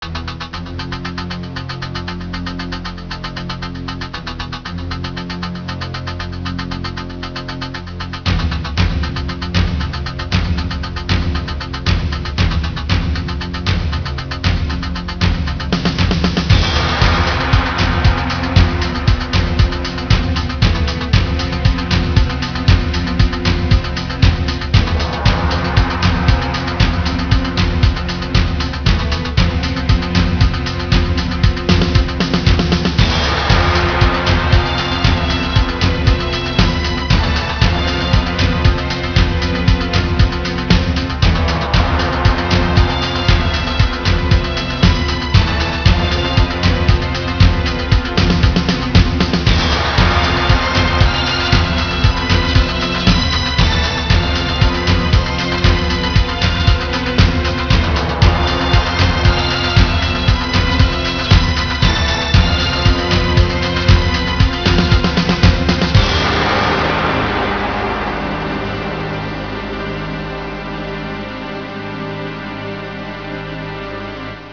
That lovely, haunting organ piece.